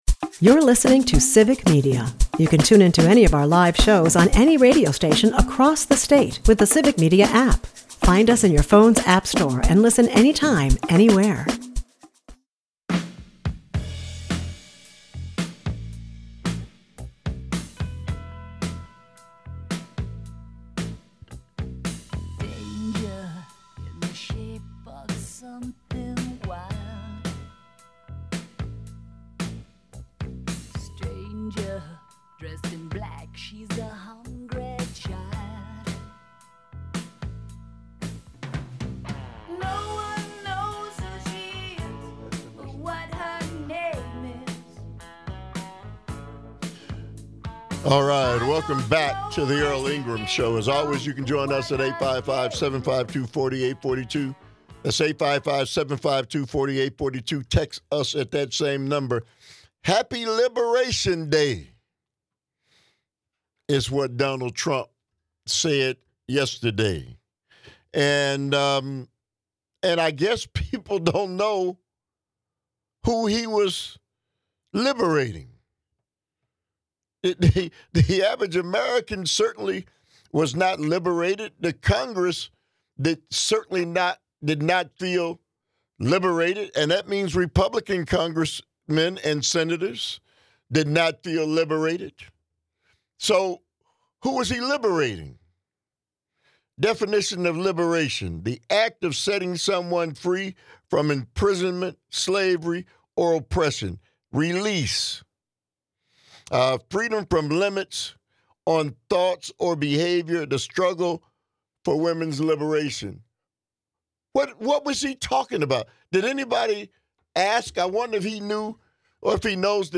engages in discussions and lively debates with individuals regarding Trump's tariffs and his governmental decisions.